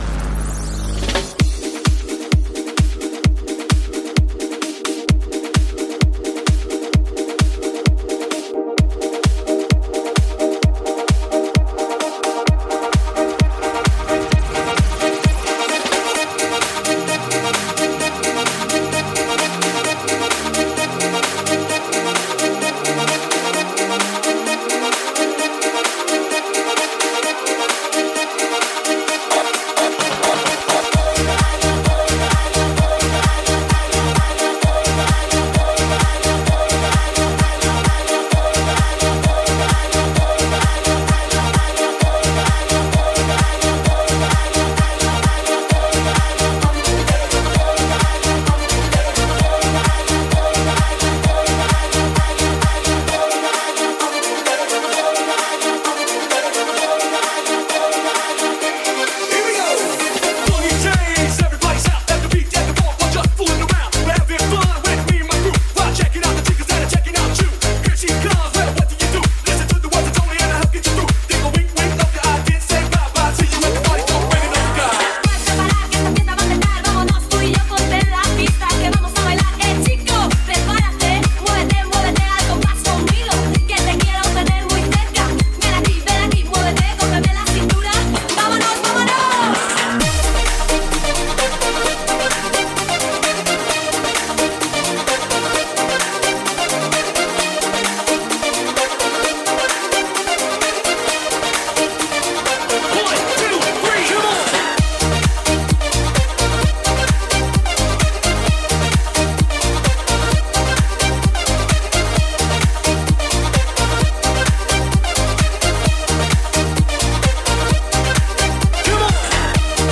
EuroDance-Mix.mp3